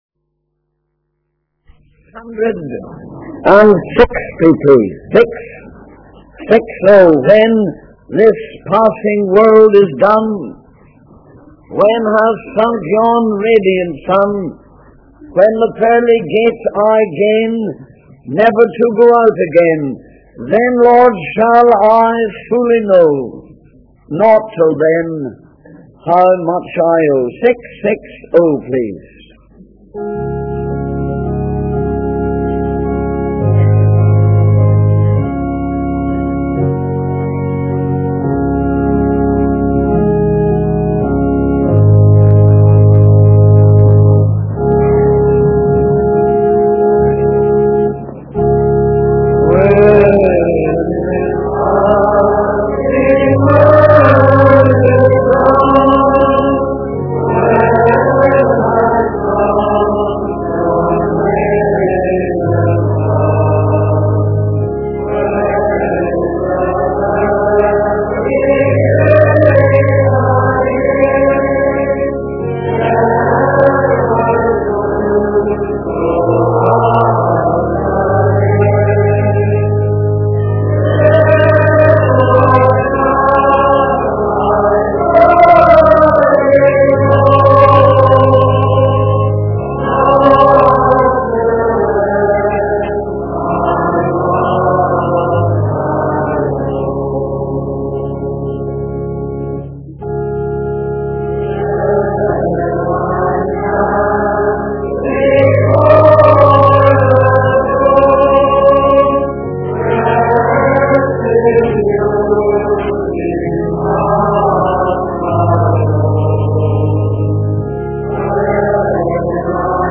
In this sermon, the preacher emphasizes the importance of speaking about Christ to those who are seeking redemption. The preacher encourages the congregation to sing hymns and praises to Emmanuel's name.